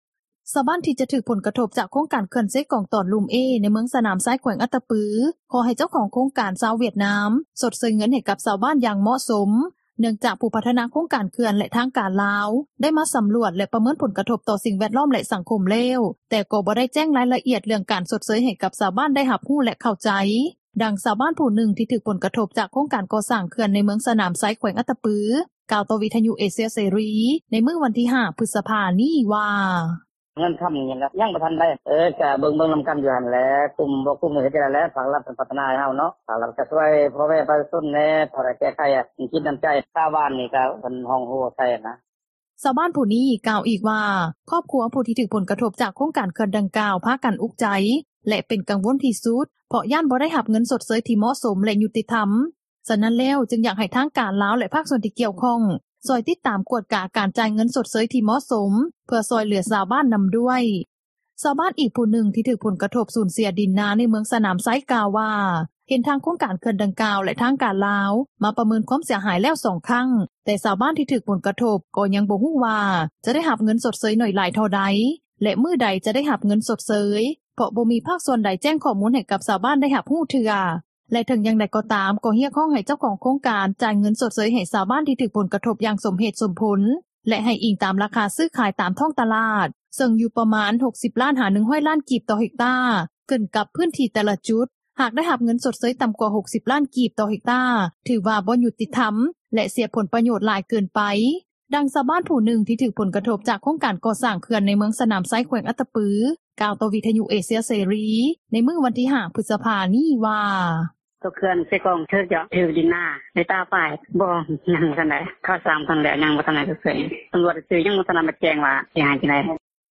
ດັ່ງຊາວບ້ານຜູ້ນຶ່ງ ທີ່ຖືກຜົລກະທົບ ຈາກໂຄງການກໍ່ສ້າງເຂື່ອນ ໃນເມືອງສນາມໄຊ ແຂວງອັດຕະປື ກ່າວຕໍ່ວິທຍຸເອເຊັຽເສຣີ ໃນມື້ວັນທີ່ 5 ພຶສພາ ນີ້ວ່າ:
ດັ່ງຊາວບ້ານຜູ້ນຶ່ງ ທີ່ຖືກຜົລກະທົບ ຈາກໂຄງກໍ່ສ້າງເຂື່ອນ ໃນເມືອງສນາມໄຊ ແຂວງອັດຕະປື ກ່າວຕໍ່ວິທຍຸເອເຊັຽເສຣີ ໃນມື້ວັນທີ່ 5 ພຶສພາ ນີ້ວ່າ: